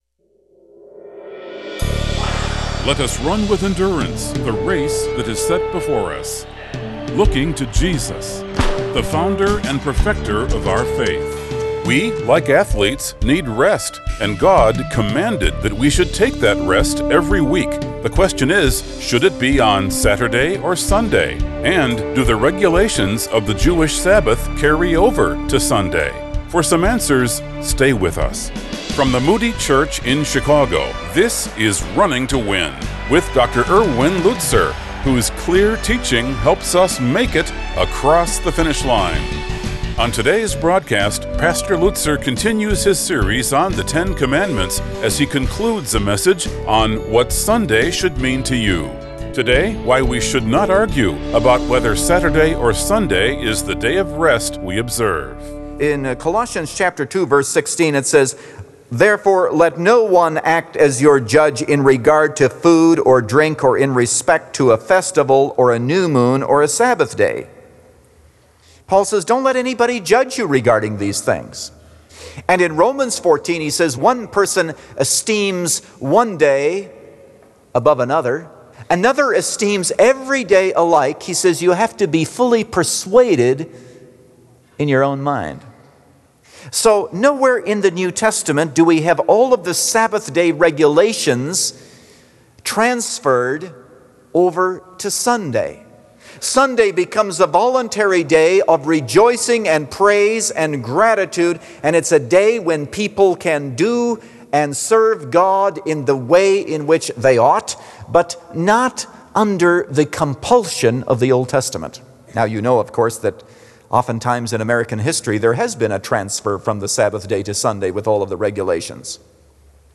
What Sunday Should Mean To You – Part 3 of 3 | Radio Programs | Running to Win - 15 Minutes | Moody Church Media